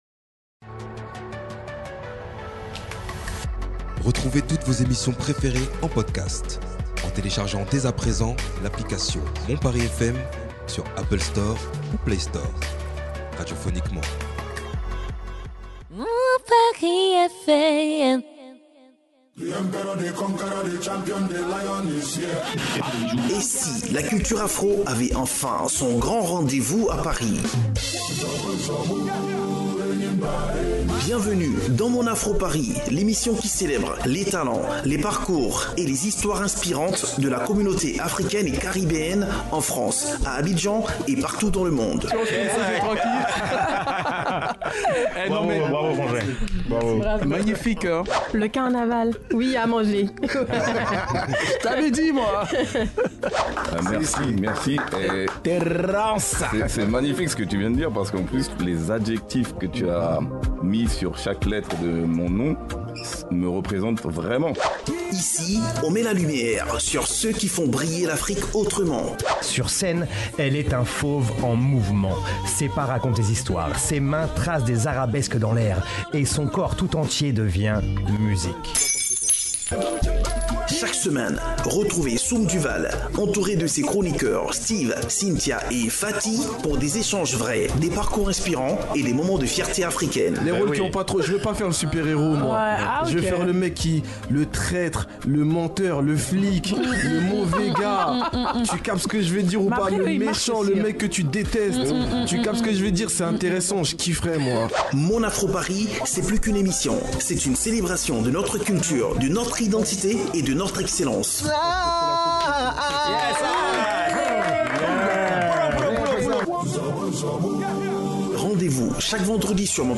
06 mars 2026 - 21:00 Écouter le podcast Télécharger le podcast Un numéro entre reggae et zouk.